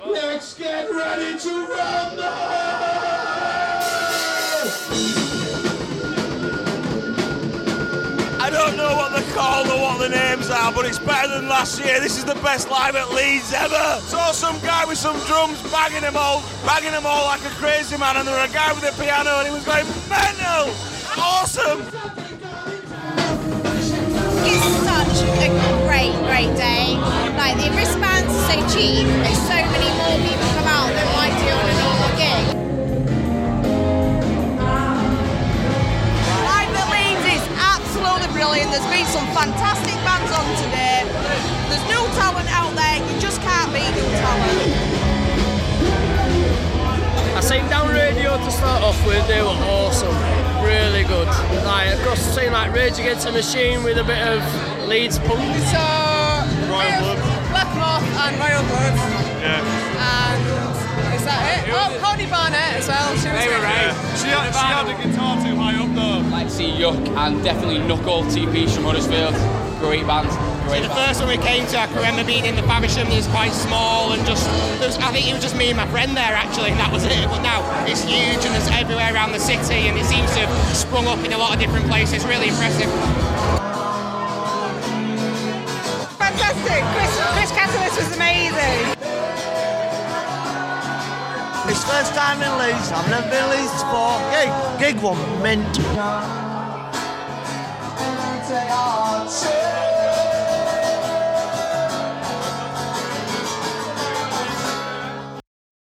Clips of the crowd at Live At Leeds on Saturday.